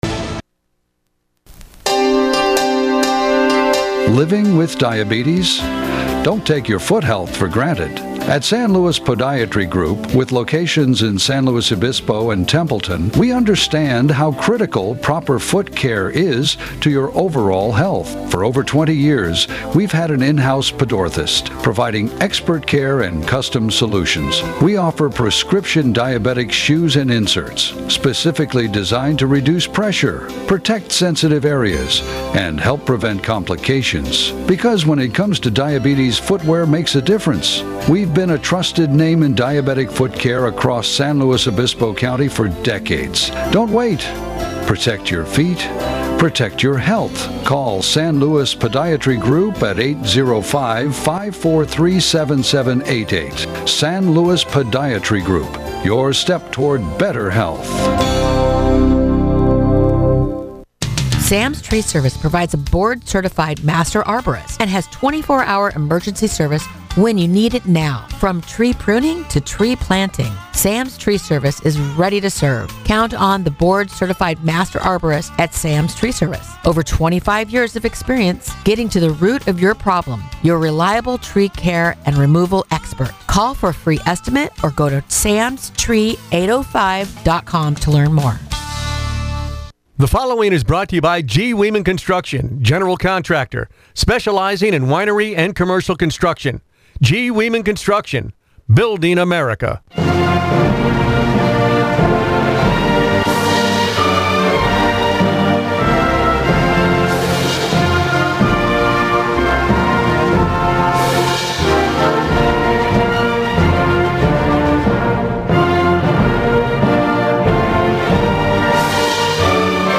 The Morning Exchange; North County’s local news show airs 6 a.m. to 9 a.m. every weekday.
Weather every hour around the clock. Call in and let your voice be heard on a number of topics.